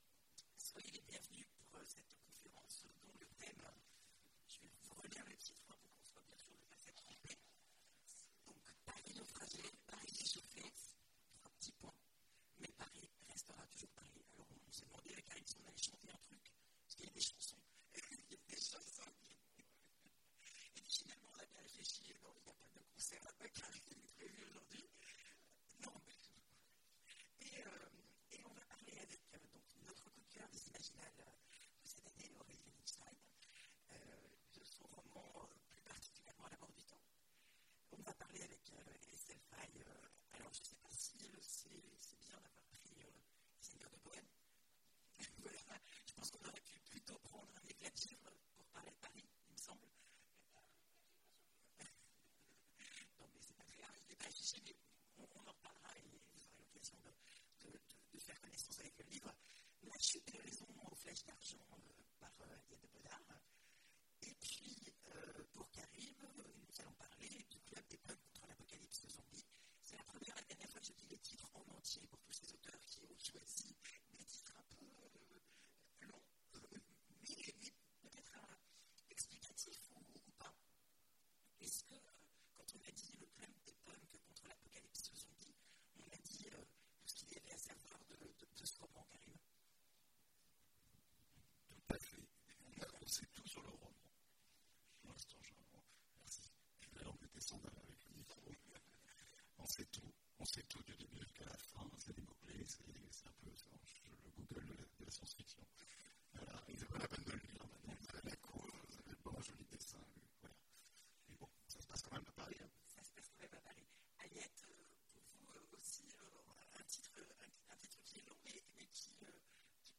Imaginales 2017 : Conférence Paris naufragé, Paris réchauffé… Mais Paris restera toujours Paris !